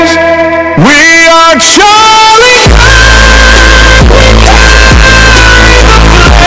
we are charlie kirk loud asf Meme Sound Effect
we are charlie kirk loud asf.mp3